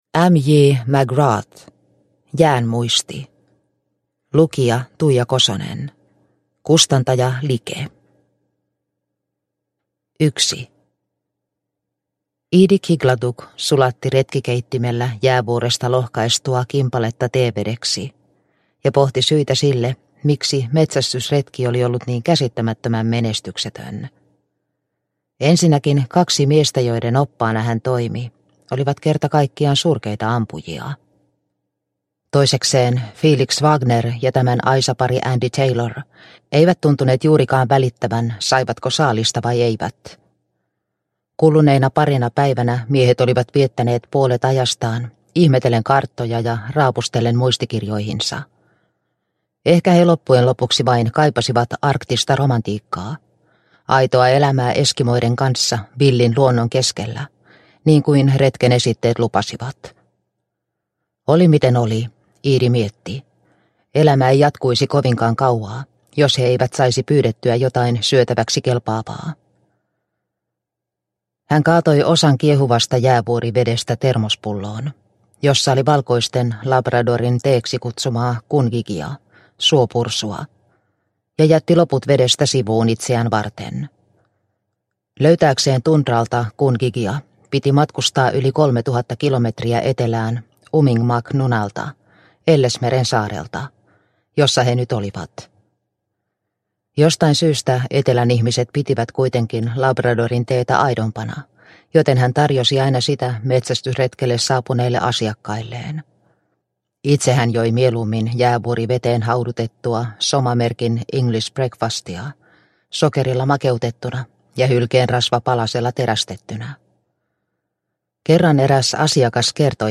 Jään muisti – Ljudbok – Laddas ner